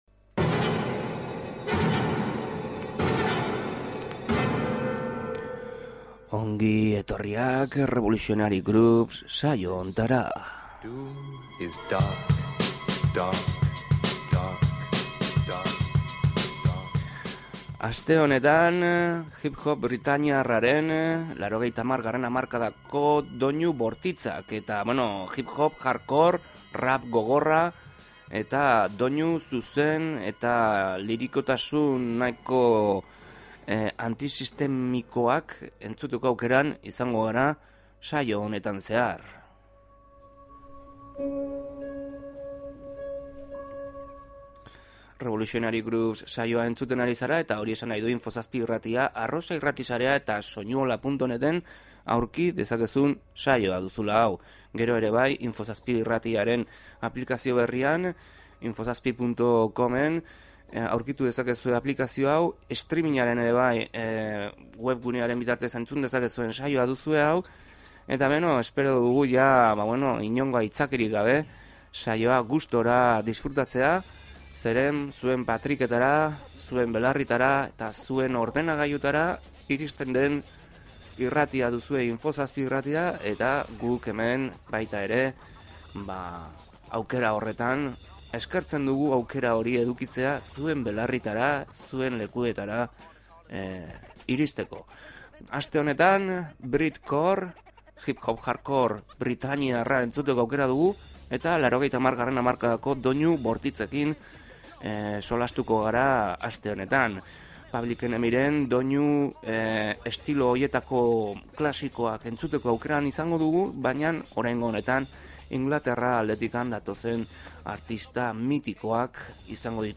REVOLUTIONARY GROOVES: Break beat eta elektro musika sesioa izan dugu entzungai Revolutionary Grooves saioan